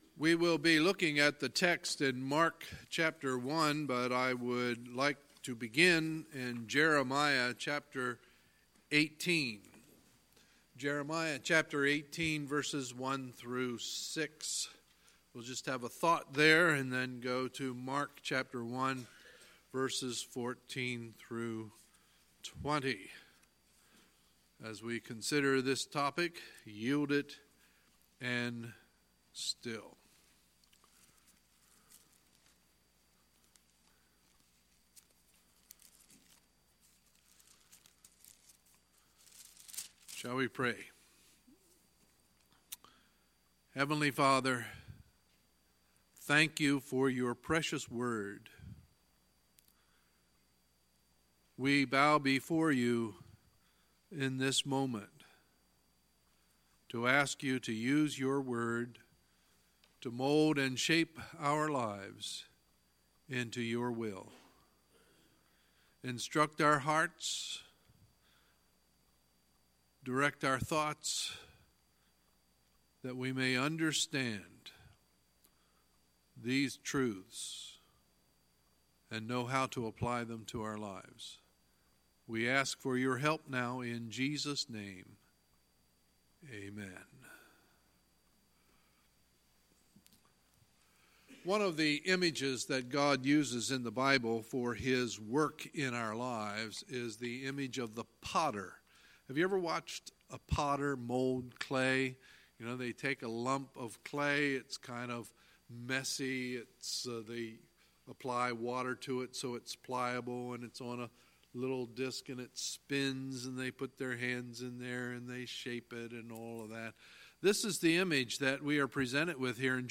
Sunday, February 3, 2019 – Sunday Morning Service
Sermons